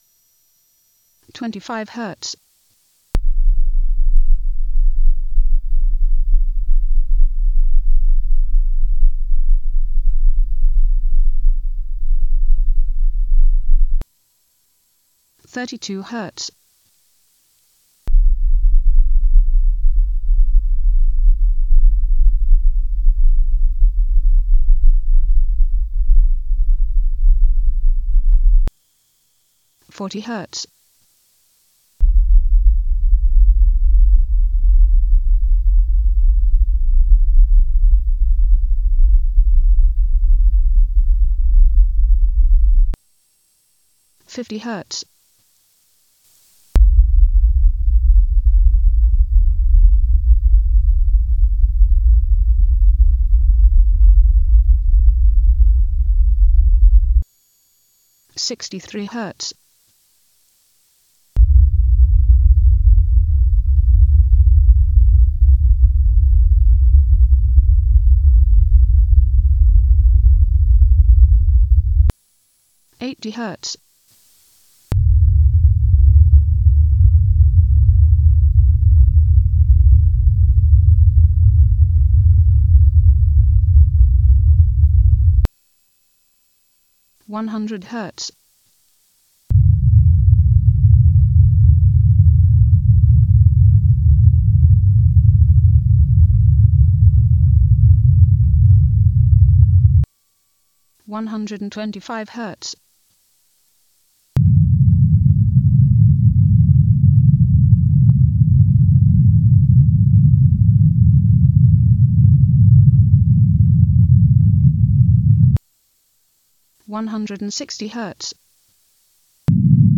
1/3 OCTAVE BANDED PINK NOISE .WAV TEST FILE
Narrow banded pink noise on center frequencies of:
bandedpinknoiseV2.wav